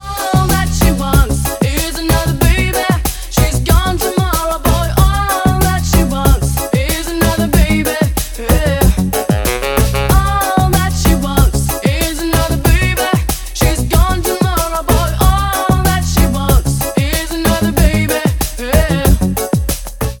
Dance Pop
90-е
регги-поп
reggae-pop